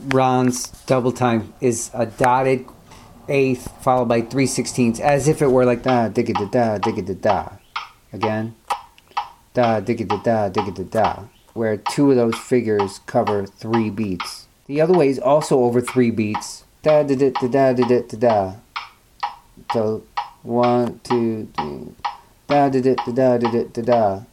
They were in fact 16th notes, but he only had 2 common rhythmic phrases and they were both grouped over 3 beats. For the music geeks among you, here are is a rhythmic transcription of them followed by a short audio sample of my scatting them.